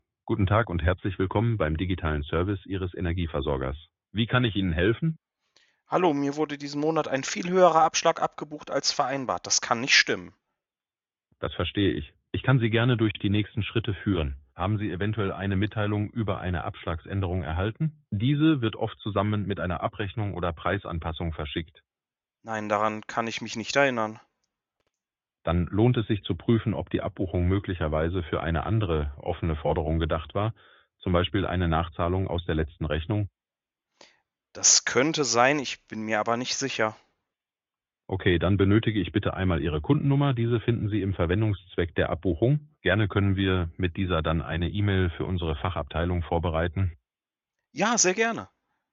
Für jeden Fall die passende KI mit der passenden Stimme:
KI-Energie.mp3